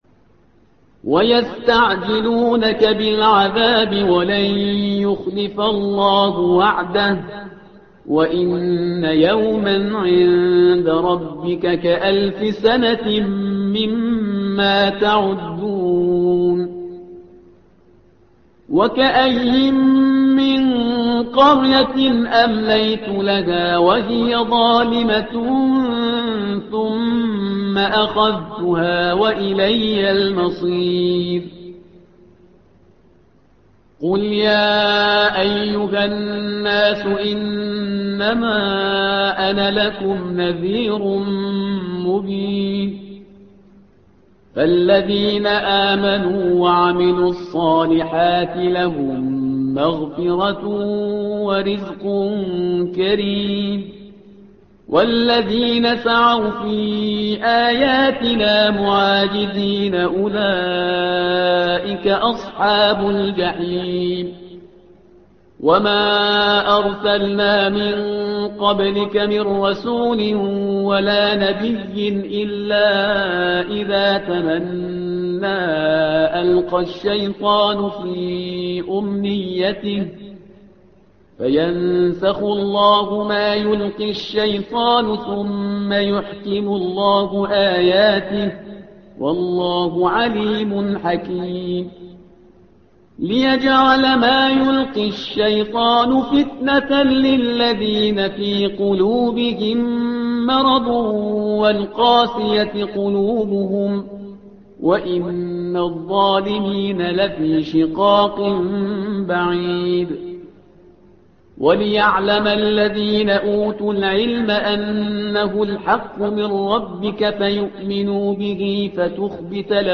الصفحة رقم 338 / القارئ